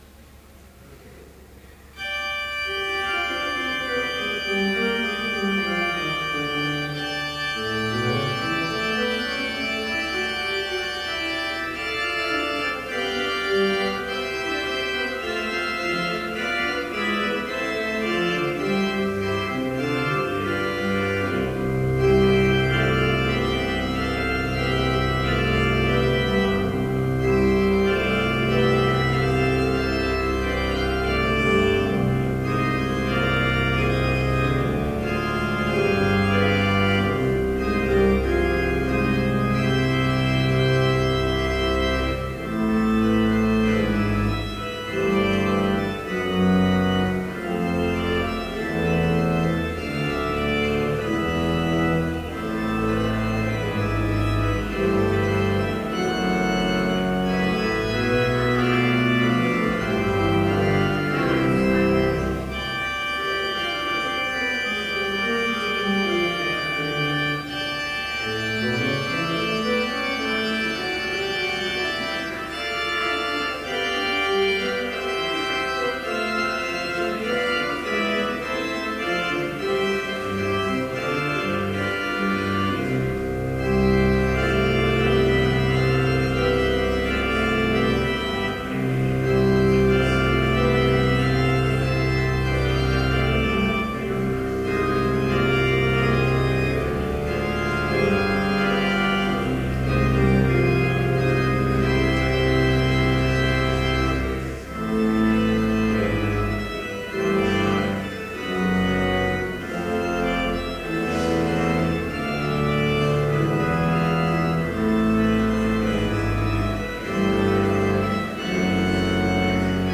Complete service audio for Chapel - April 24, 2014